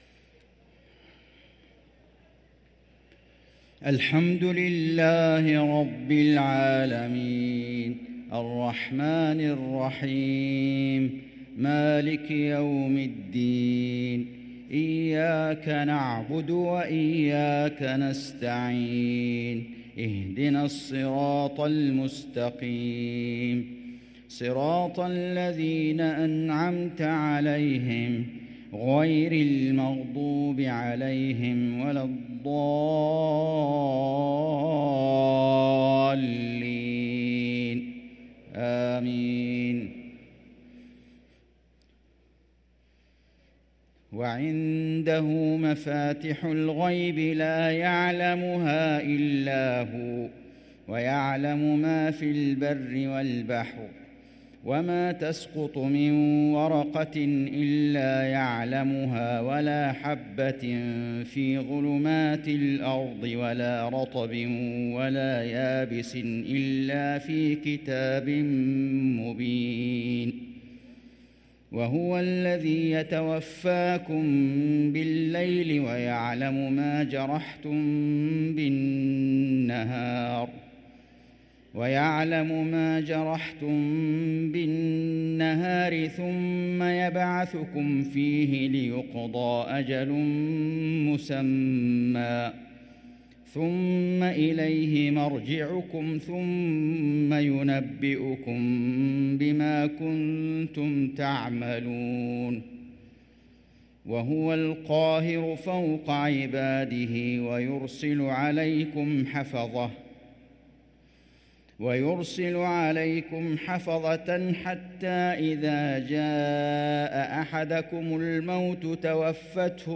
صلاة العشاء للقارئ فيصل غزاوي 20 رجب 1444 هـ